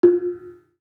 Gamelan Sound Bank
Kenong-dampend-F3-f.wav